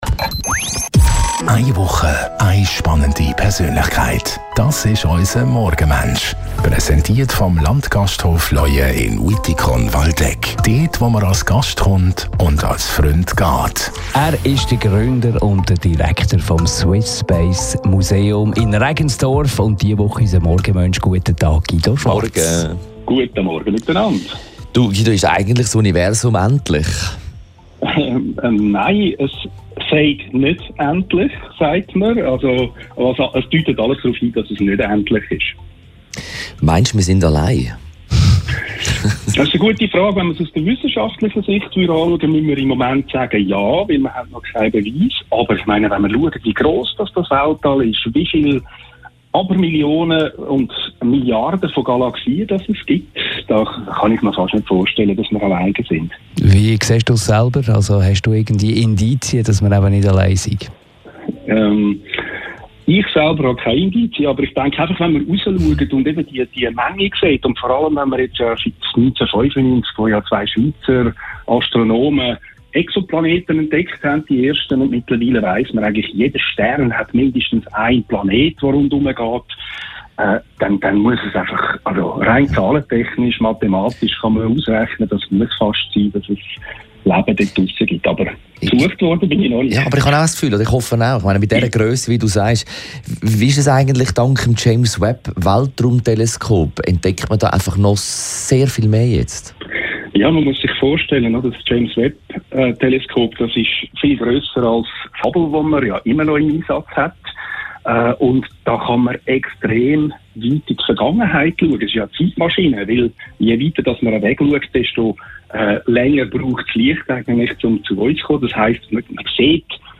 telefonieren jeden Morgen von Montag bis Freitag nach halb 8 Uhr mit einer interessanten Persönlichkeit.